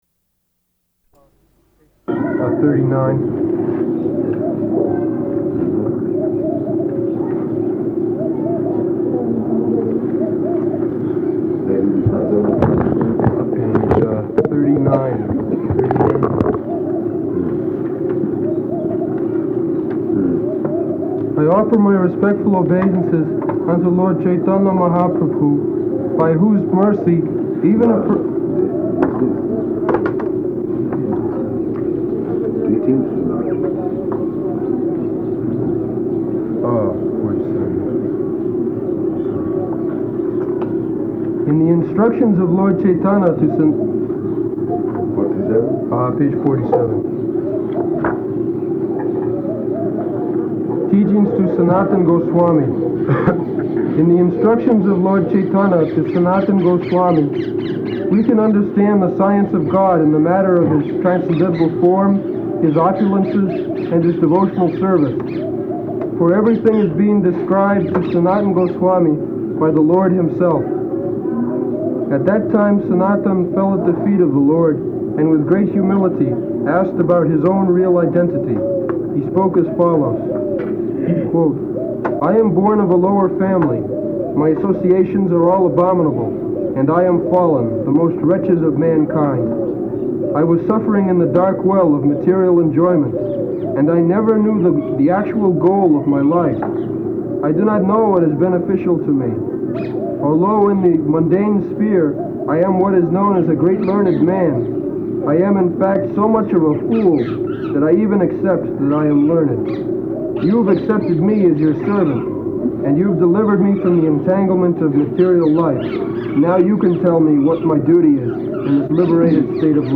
October 19th 1972 Location: Vṛndāvana Audio file